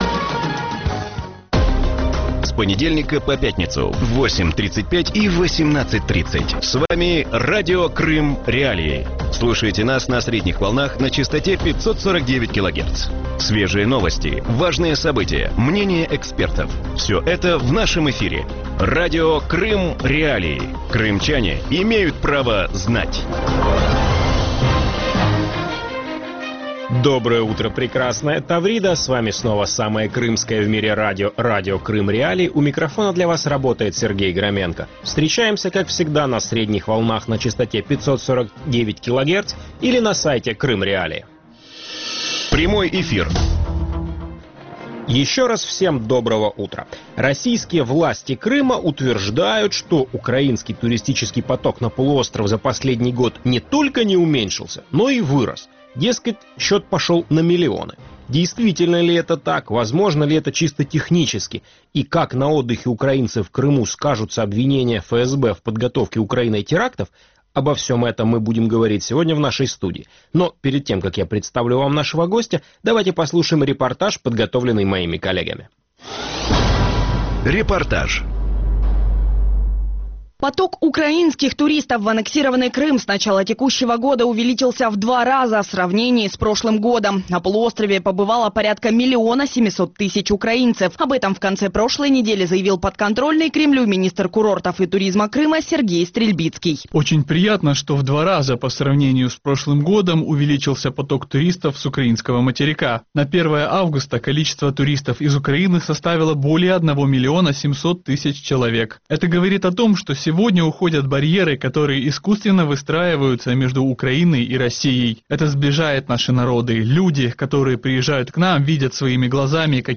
В аннексированном Россией Севастополе нет украинских туристов, утверждают местные жители. Об этом рассказала в утреннем эфире Радио Крым.Реалии позвонившая в студию жительница Севастополя.